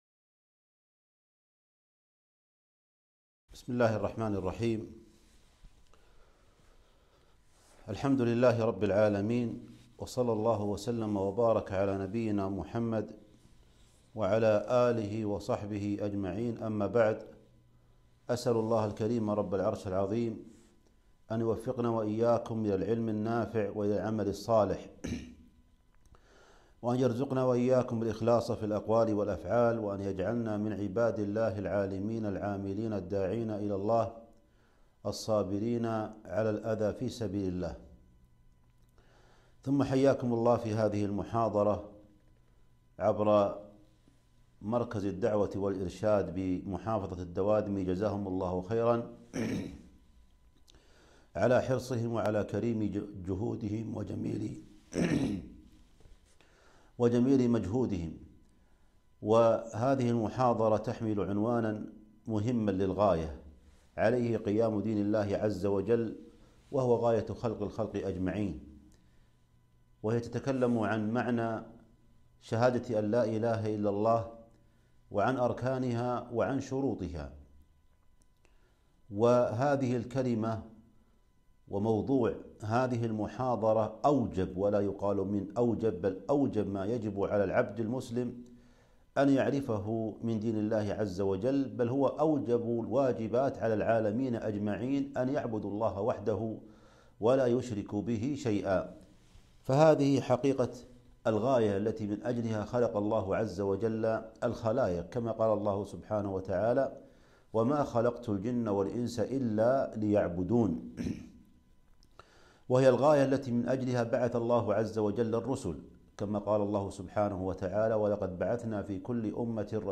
محاضرة - معنى لا إله إلا الله وشروطها وأركانها